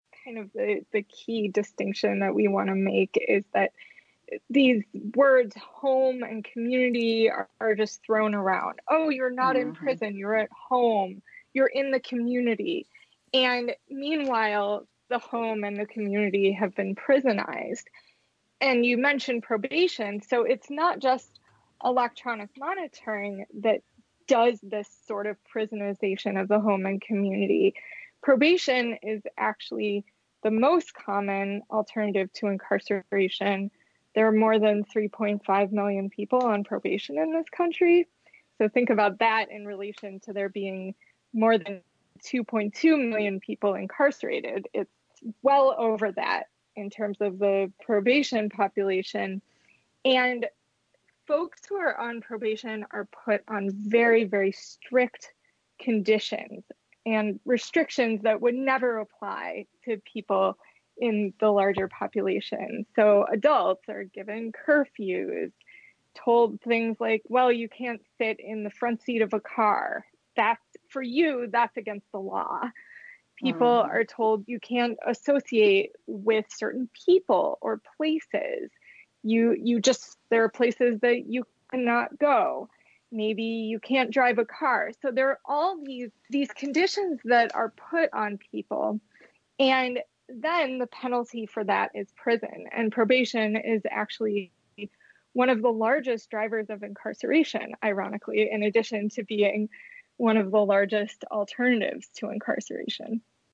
In-Depth Interview